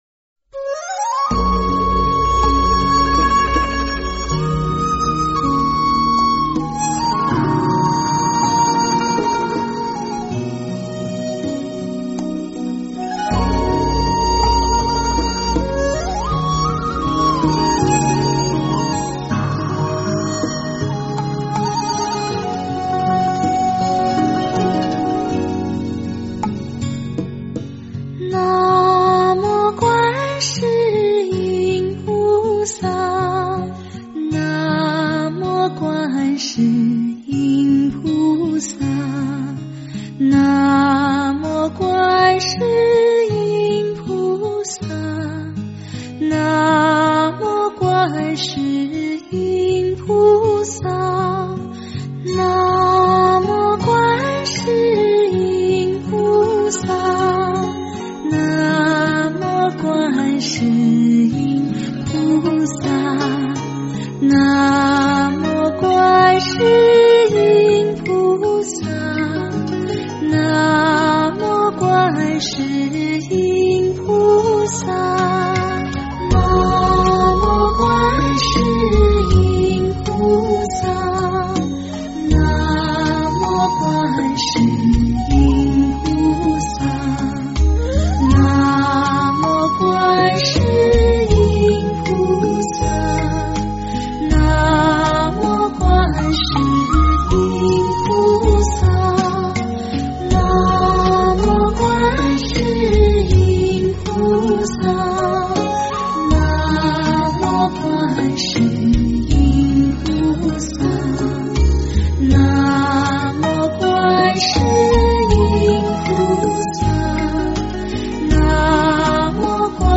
观音圣号《心经》 诵经 观音圣号《心经》--佛经音乐 点我： 标签: 佛音 诵经 佛教音乐 返回列表 上一篇： 解脱 下一篇： 平湖秋月 相关文章 南无阿弥陀佛--无名氏 南无阿弥陀佛--无名氏...